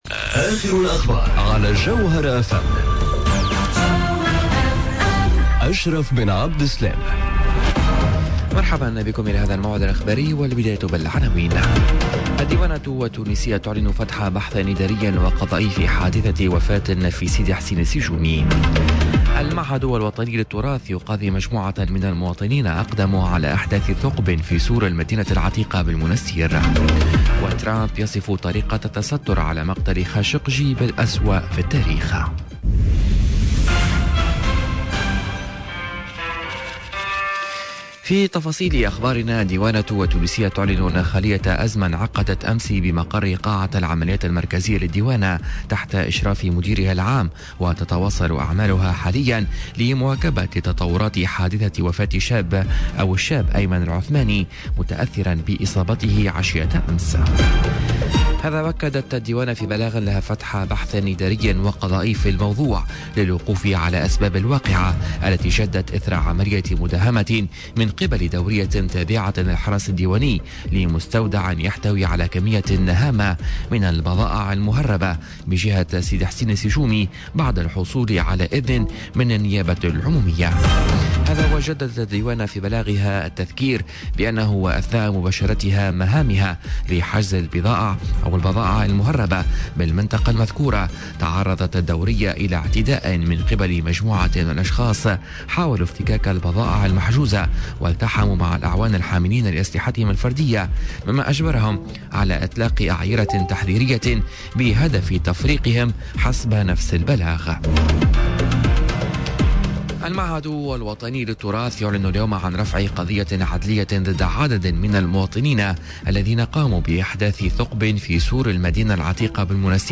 Journal Info 12h00 du mercredi 24 octobre 2018